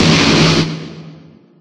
Explosion1.ogg